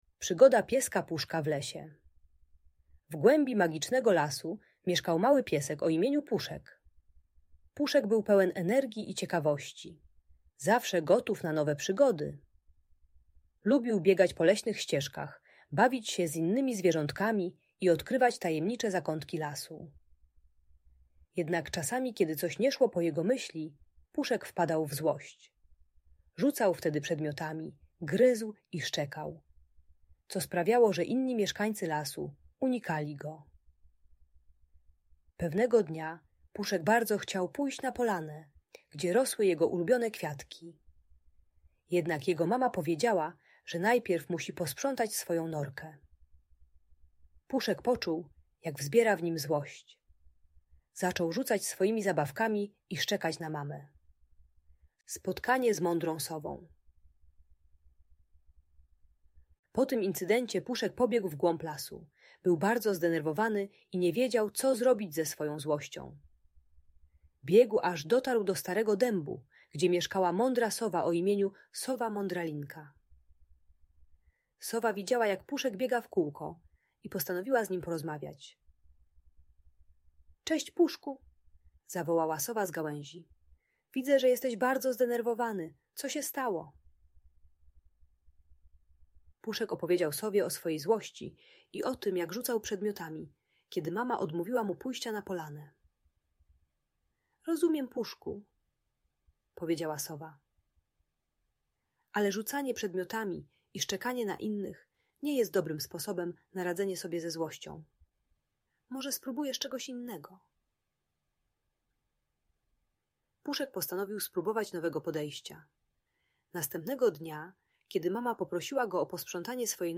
Przygoda Pieska Puszka: Magiczna historia w lesie - Audiobajka